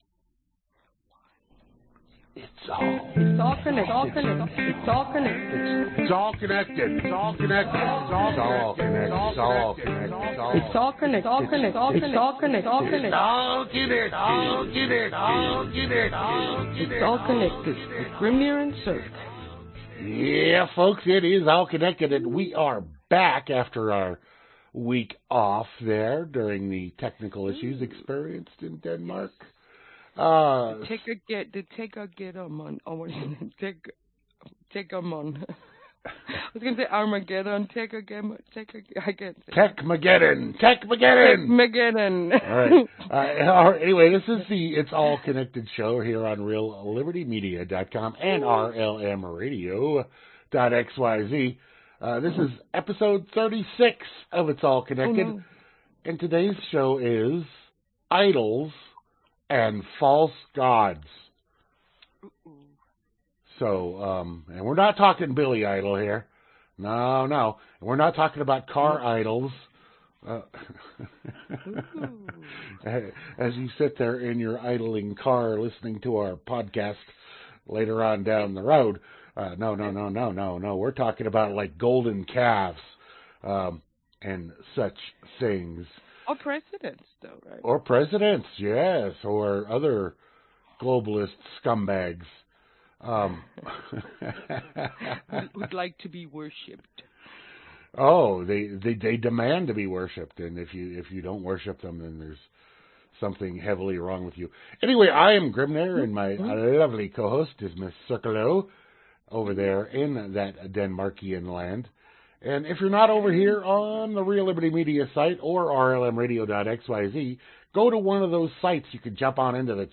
Genre Talk